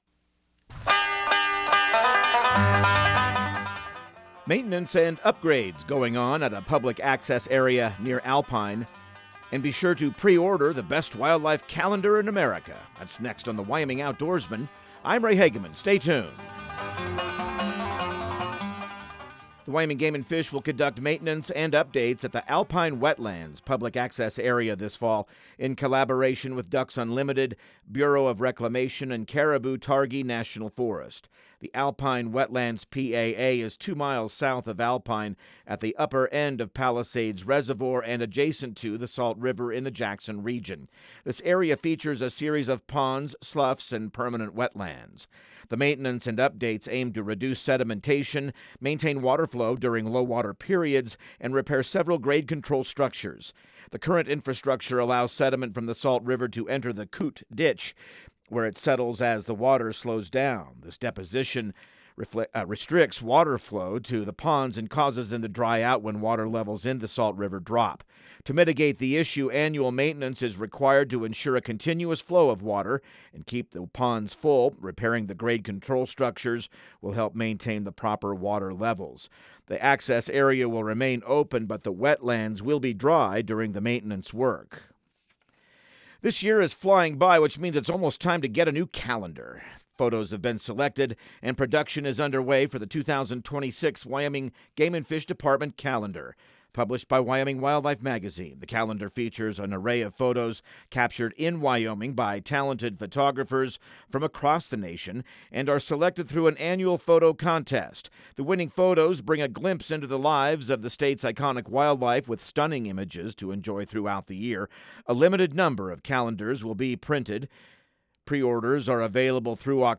Radio News | Week of September 22